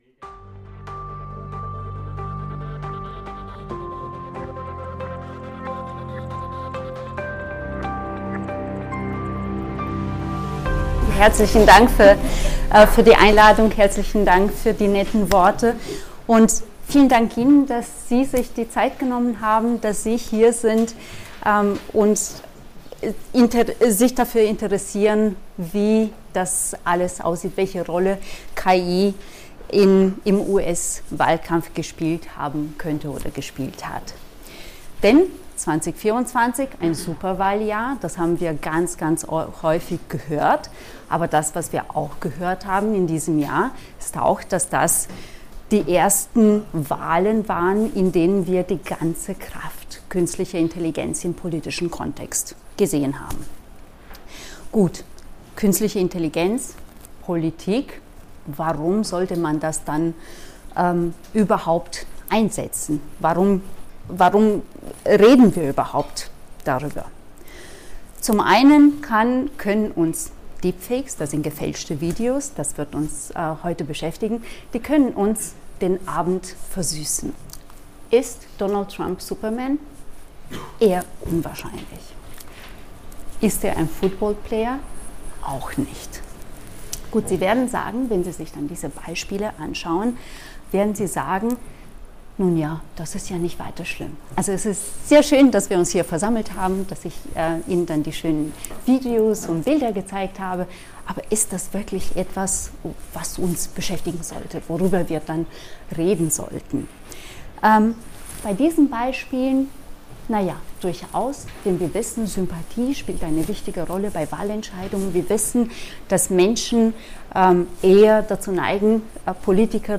Vortrag
am Standort SoWi, Universität Innsbruck